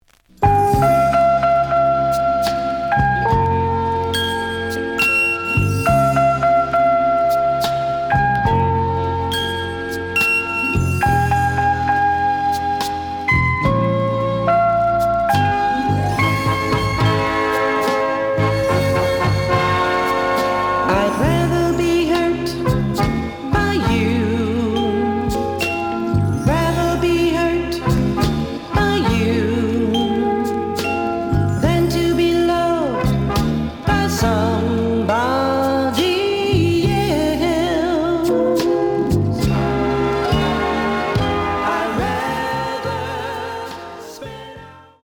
試聴は実際のレコードから録音しています。
●Format: 7 inch
●Genre: Soul, 70's Soul